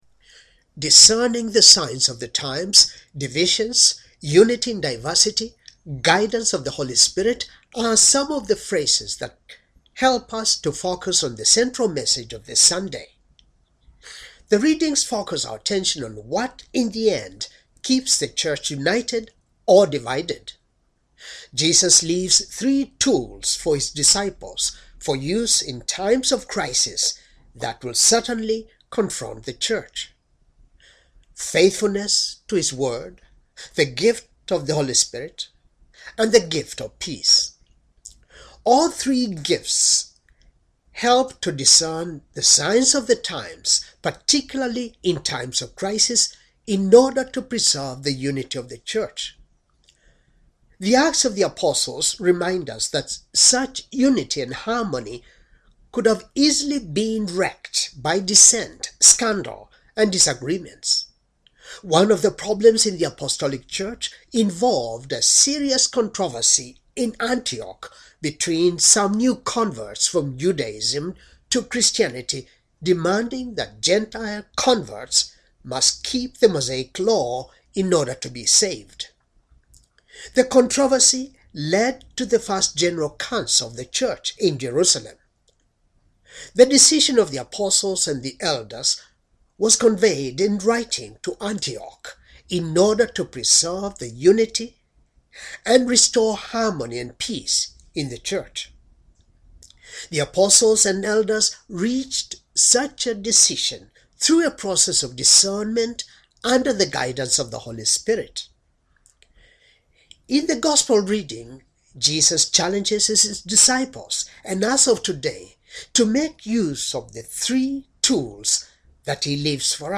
Homily for the Sixth Sunday of Easter, Year C